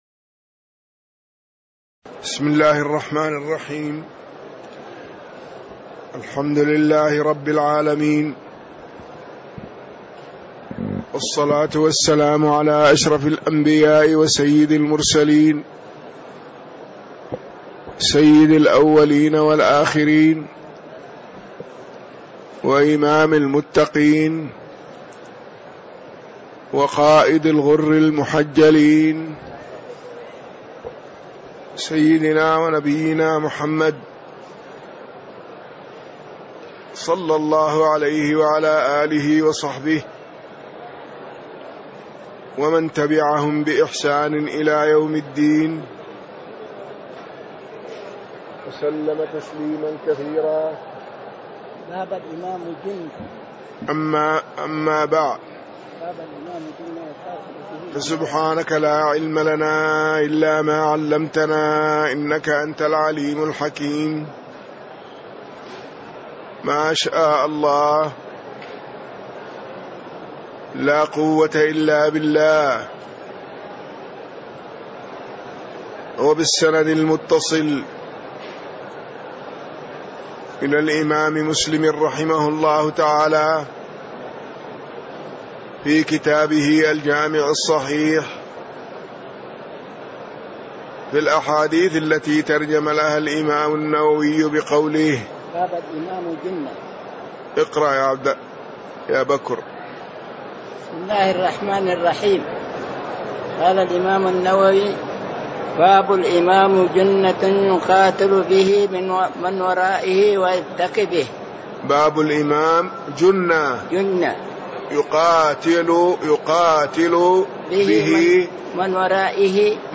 تاريخ النشر ٣٠ صفر ١٤٣٦ هـ المكان: المسجد النبوي الشيخ